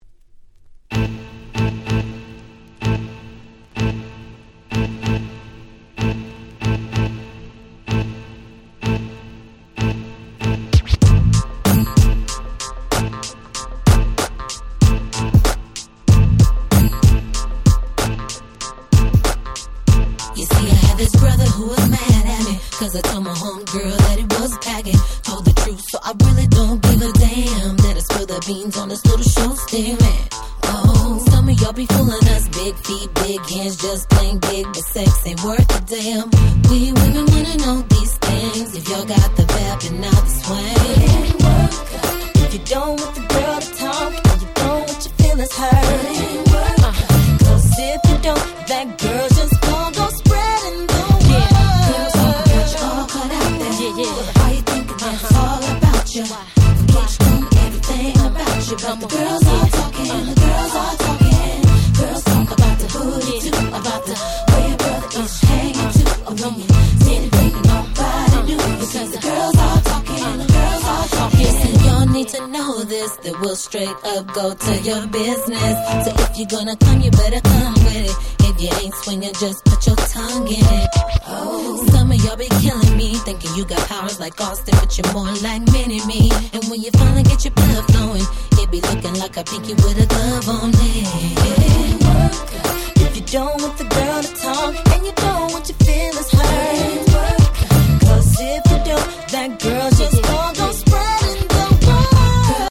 02' Smash Hit R&B !!